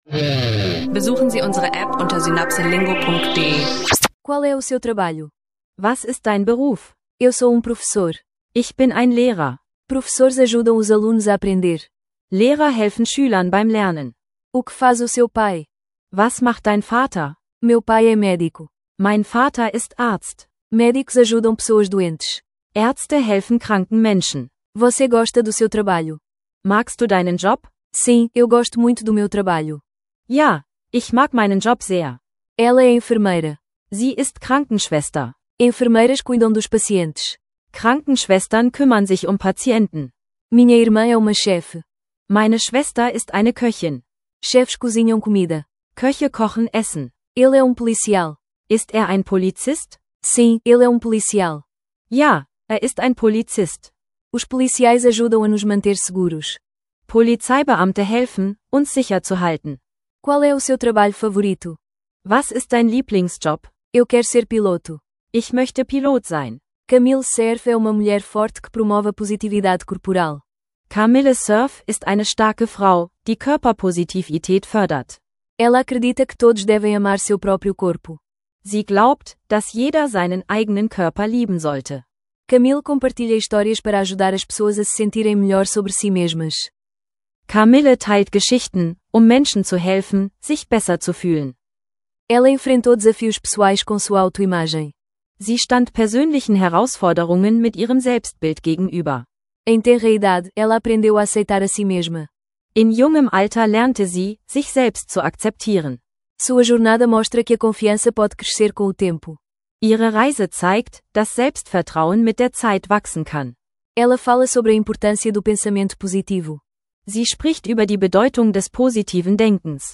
Portugiesisch lernen leicht gemacht mit praxisnahen Dialogen zu Berufen, Körperpositivität und gesellschaftlicher Verantwortung. Dieser Folge bietet eine abwechslungsreiche Mischung aus Vokabeln, nützlichen Phrasen und motivierenden Inhalten zum Portugiesisch lernen mit Podcast.